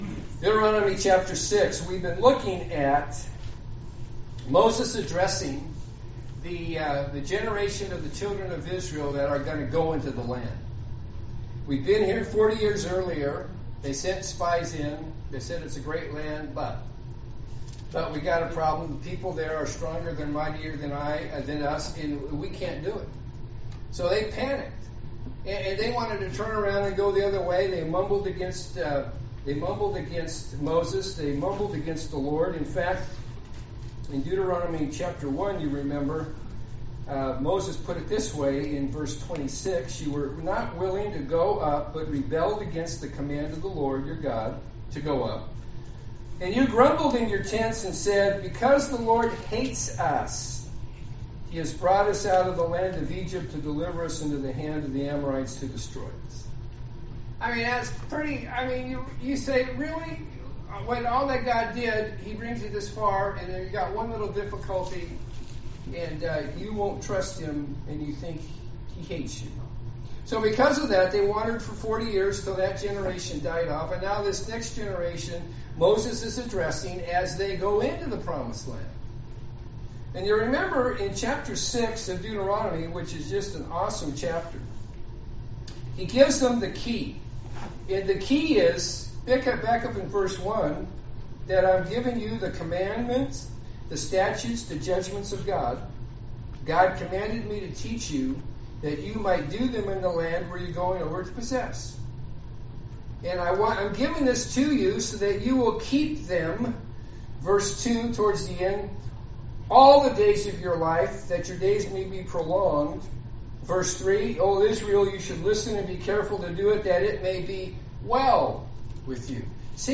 To Listen to Sermon only from the Worship Service, click here